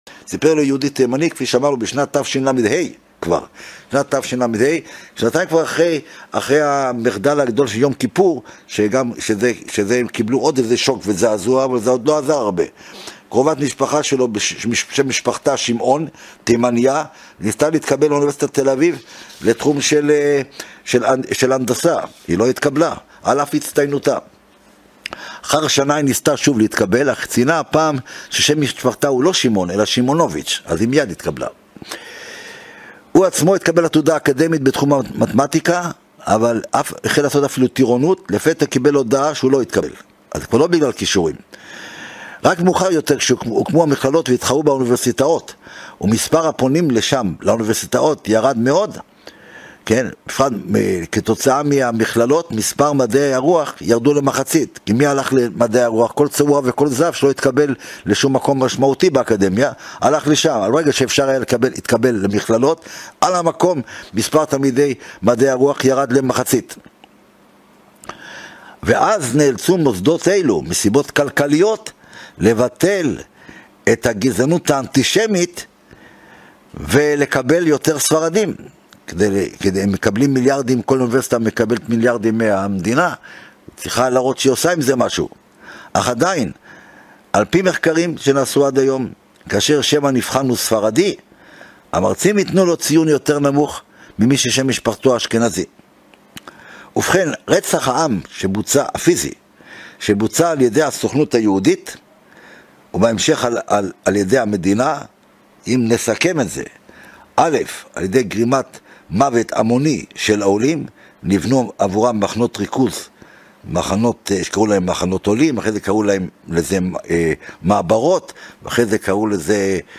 הרצאה 10 - ארבעה שואות שיצרה הציונות